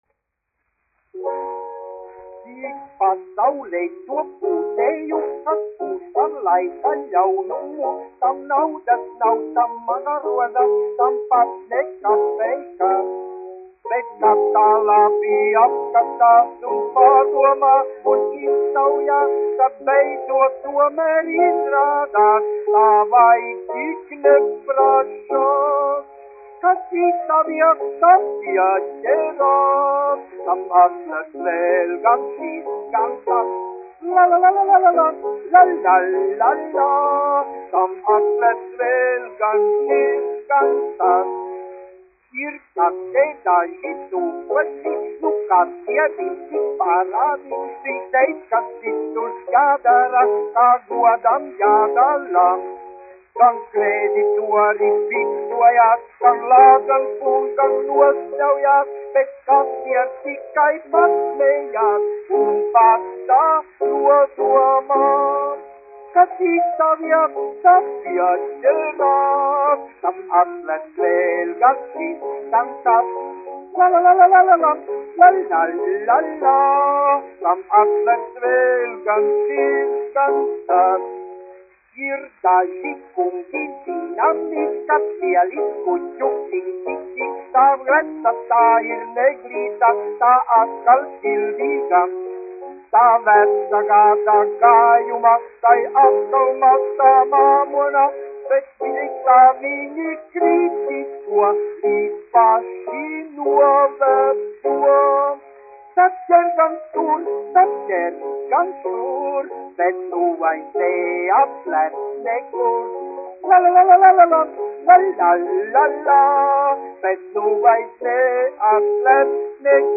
1 skpl. : analogs, 78 apgr/min, mono ; 25 cm
Populārā mūzika
Humoristiskās dziesmas
Skaņuplate